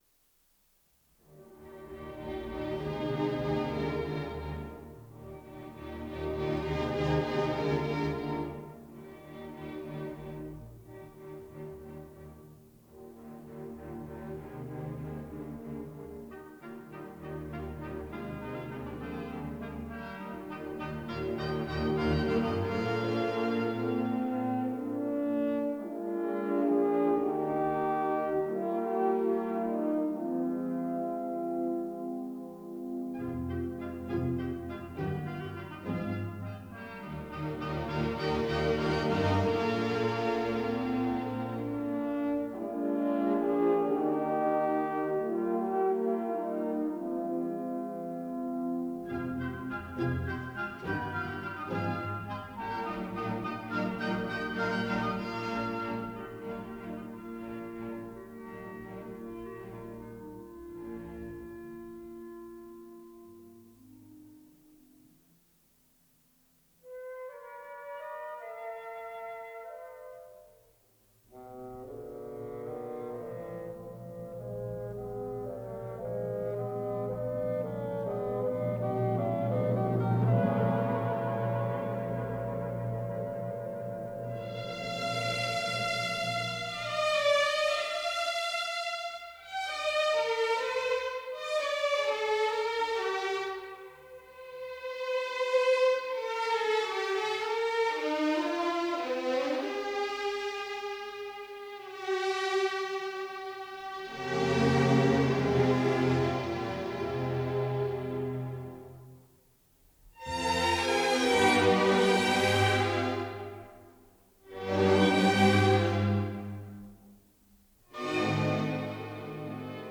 Recorded May 1964 at Sofiensaal, Vienna